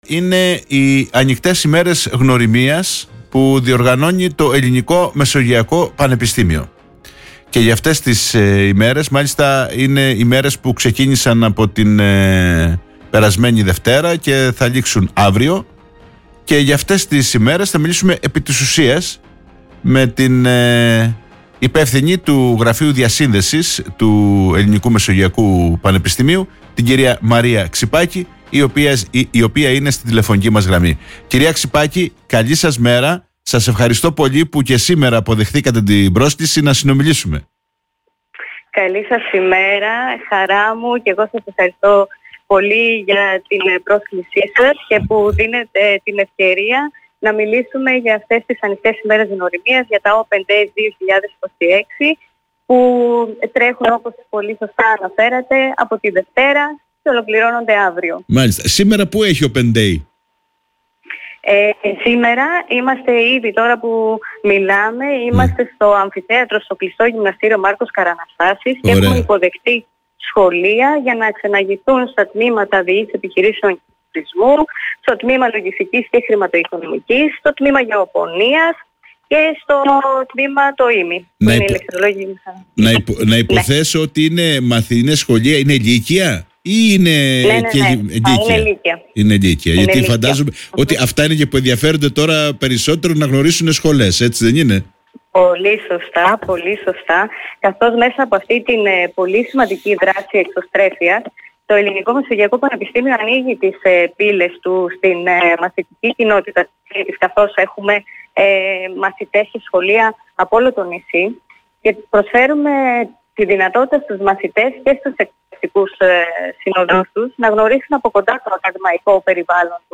μιλώντας στην εκπομπή “Όμορφη Μέρα”